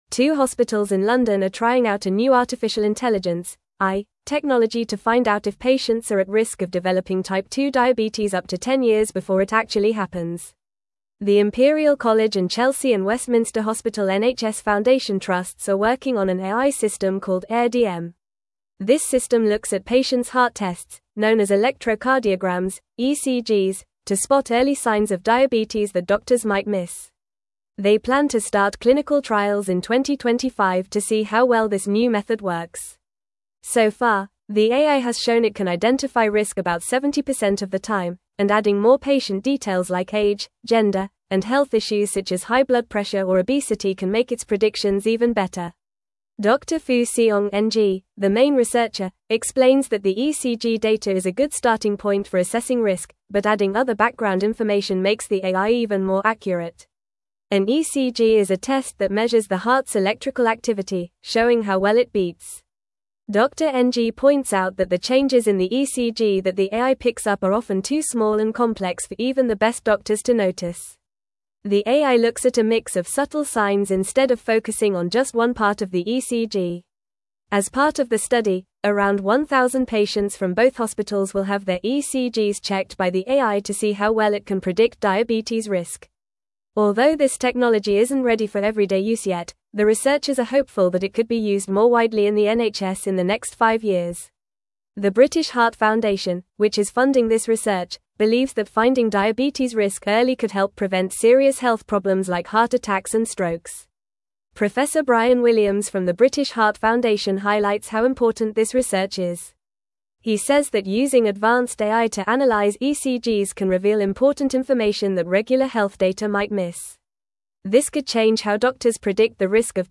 Fast
English-Newsroom-Upper-Intermediate-FAST-Reading-AI-System-Predicts-Type-2-Diabetes-Risk-Early.mp3